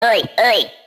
oi oi sound effects